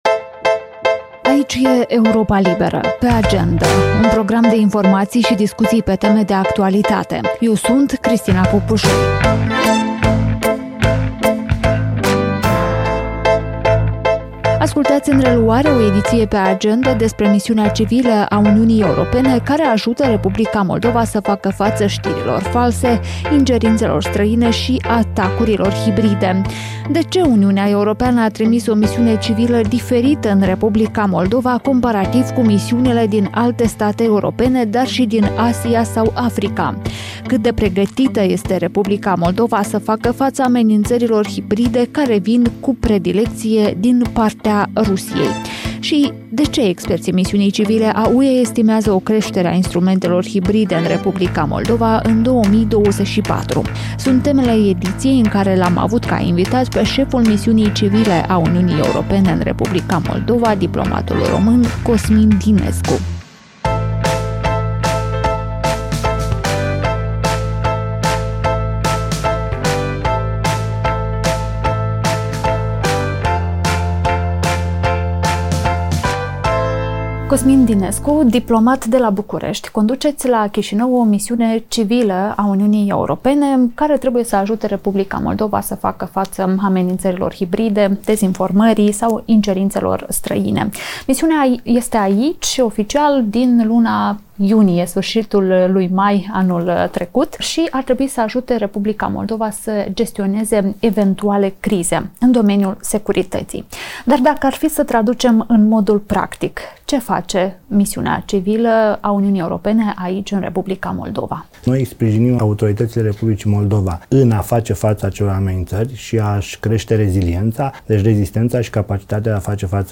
Șeful misiunii civile a UE în R. Moldova, Cosmin Dinescu, vorbește în podcastul Europei Libere „Pe agendă”, prin ce este diferită misiunea civilă trimisă în R. Moldova de alte misiuni, cât de pregătită este R. Moldova să facă față amenințărilor hibride.